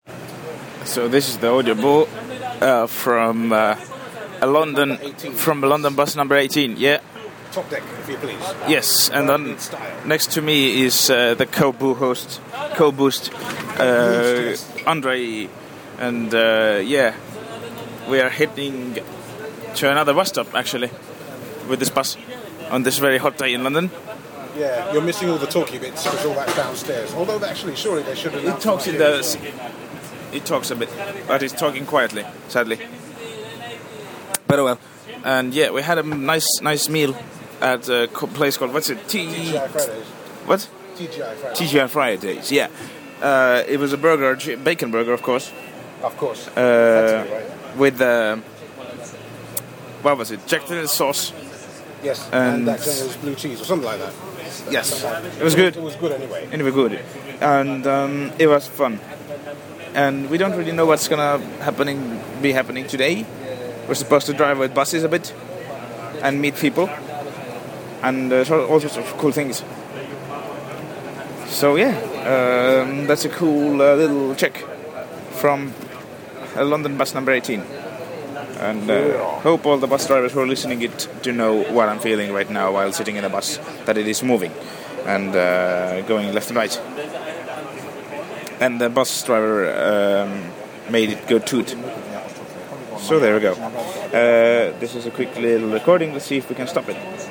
A quick check in from a London bus